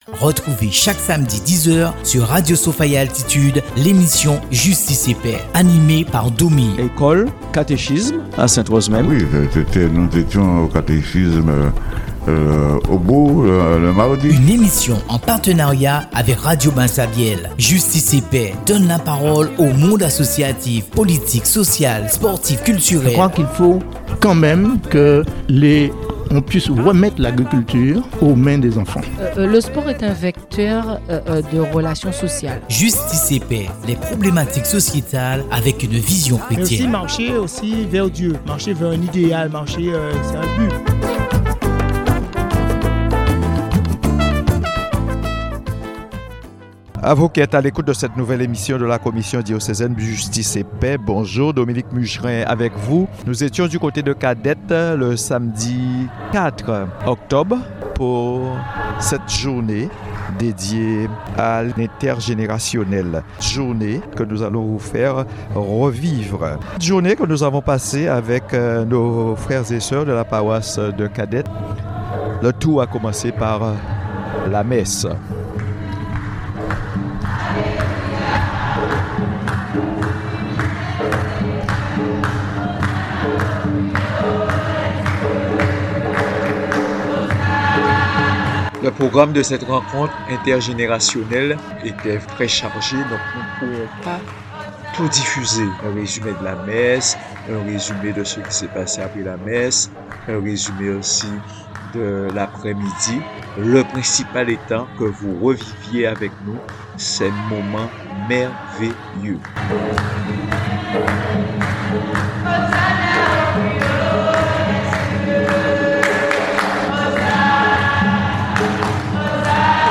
Une belle journée intergénérationnelle à la paroisse Notre Dame du Rosaire à revivre...